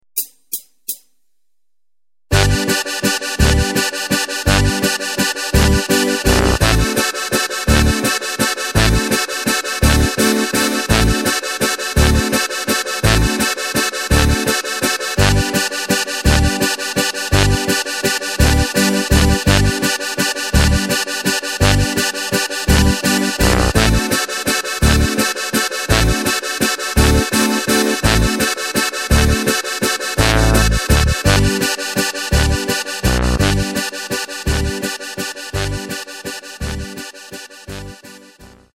Takt:          3/4
Tempo:         168.00
Tonart:            G
Walzer für Steirische Harmonika!
Playback Demo